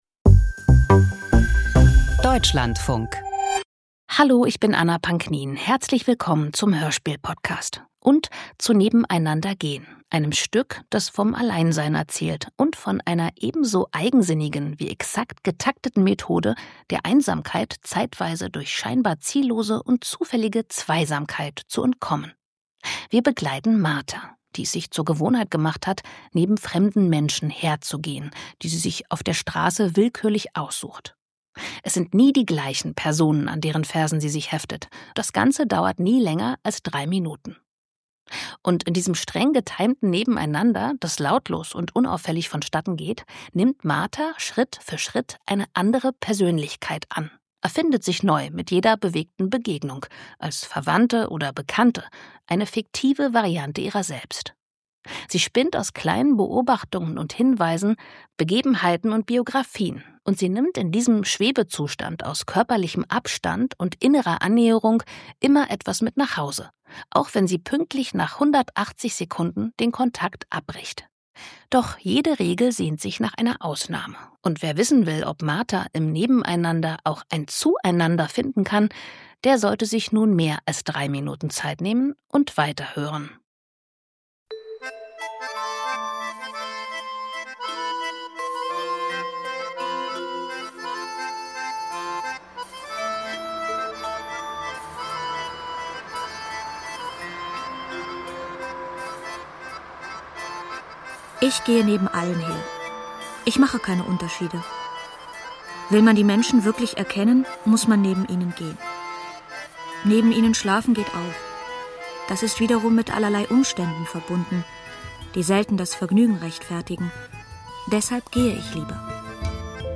"Nebeneinander gehen". Hörspiel